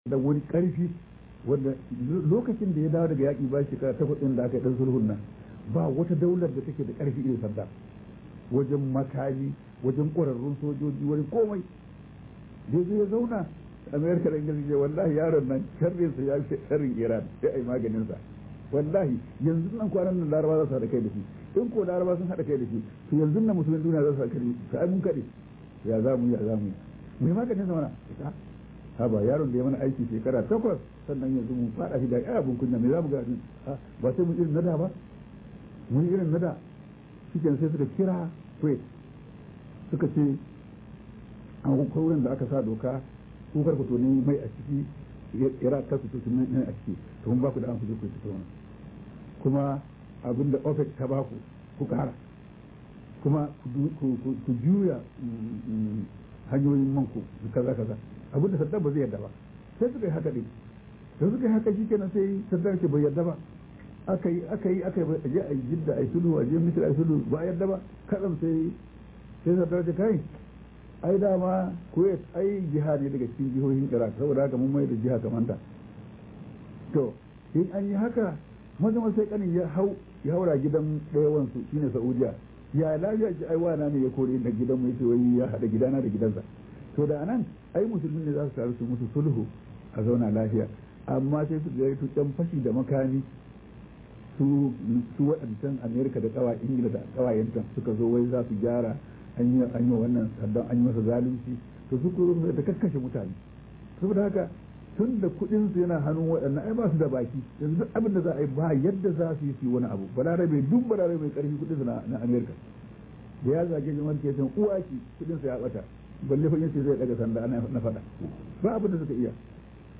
008 Sheikh Interview On Tv During Ramadan 2007 Kaduna.mp3 |SHEIKH DAHIRU USMAN BAUCHI OFR